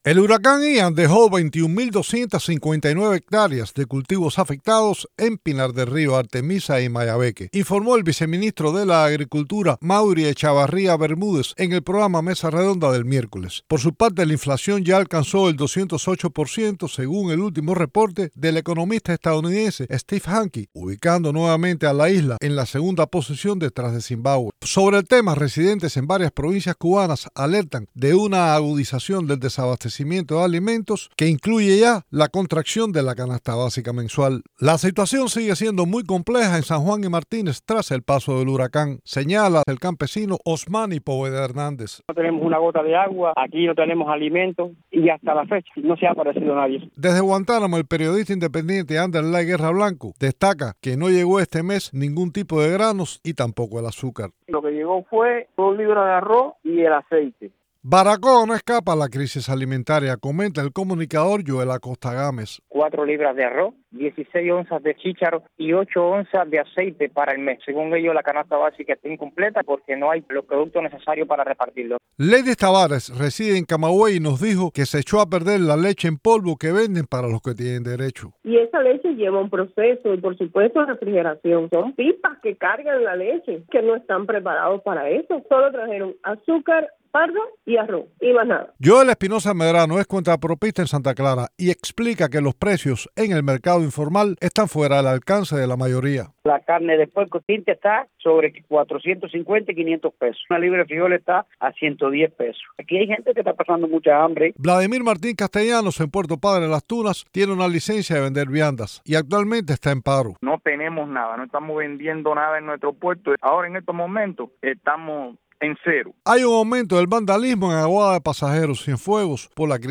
entrevistó a cubanos en varias localidades de la isla